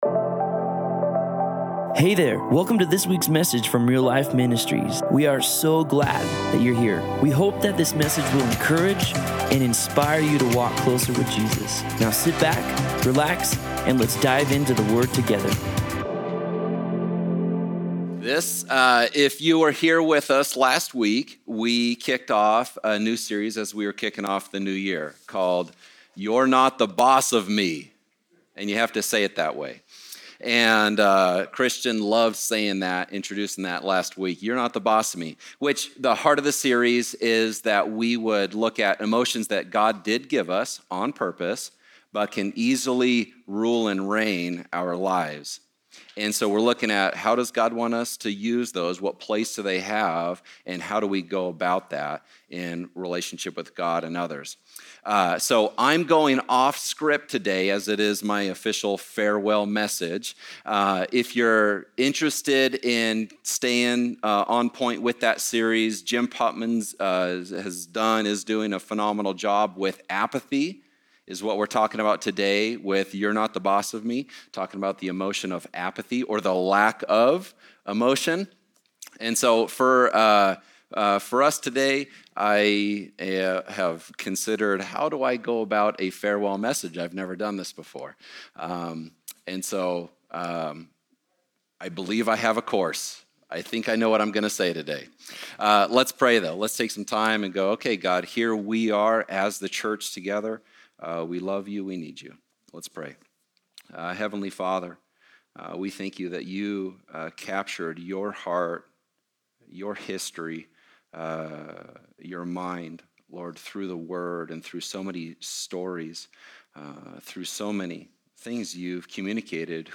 Other Sermon